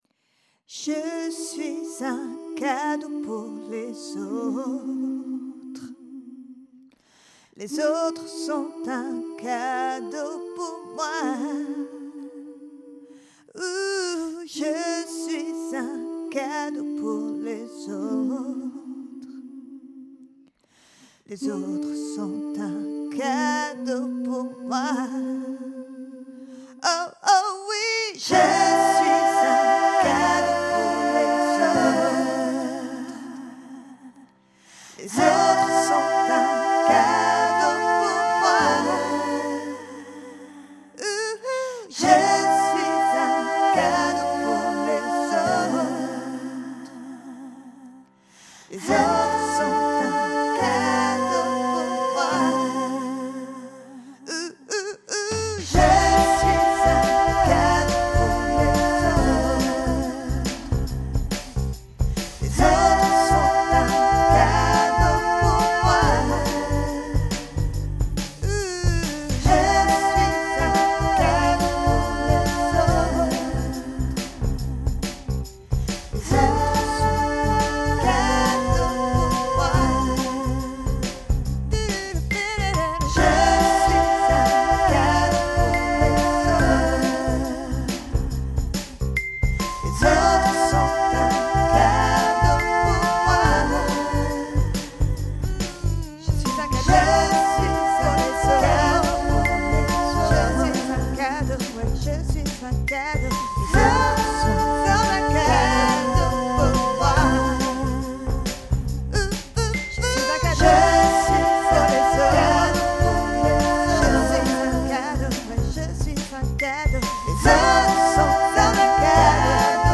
Cela fait plusieurs années que j'écris, compose et interprète des mantras en français.
Celui-ci s'appelle Je suis un cadeau pour les autres, les autres sont un cadeau pour moi. Tout l'intérêt des mantras, c'est qu'ils sont répétitifs, ils restent en tête et permettent d'avoir une pensée positive qui s'imprègne en nous.